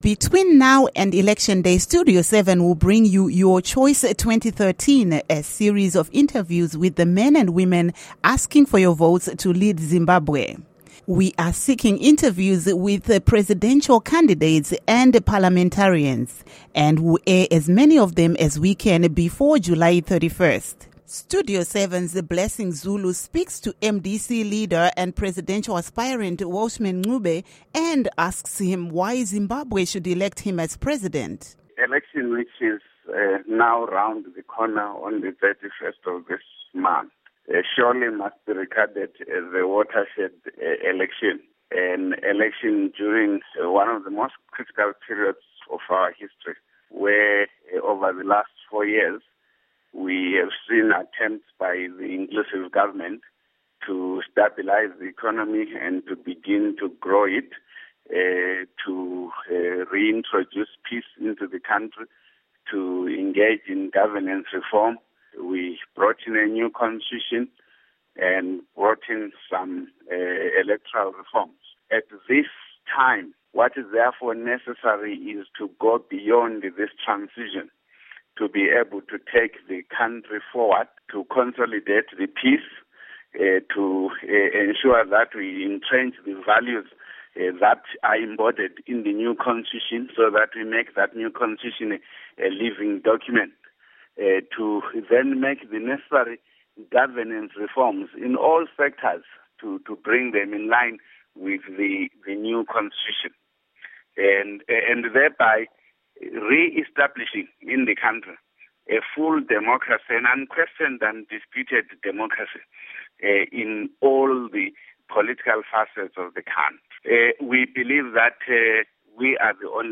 Interview With Welshman Ncube